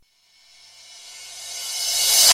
电子逆向碰撞 (1)
描述：数字反转镲片
Tag: 鼓数字化 碰撞转